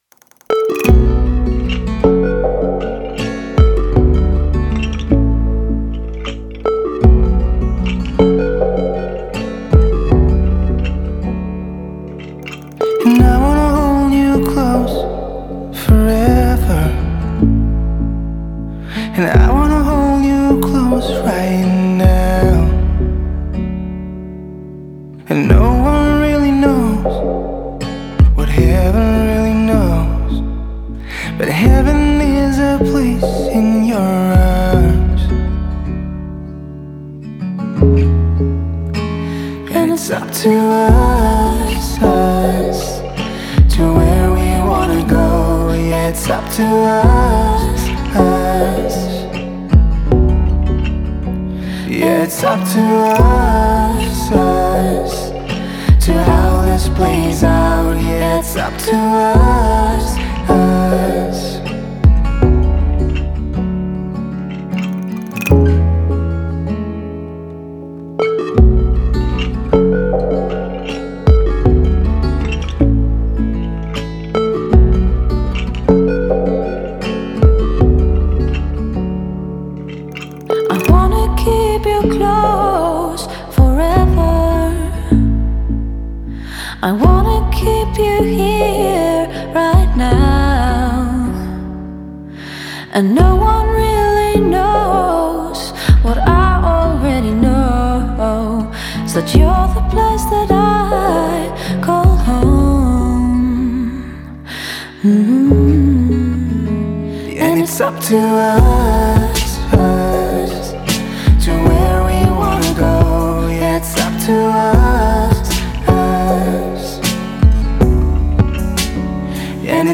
Genre: pop, alternativernb, alternativepop.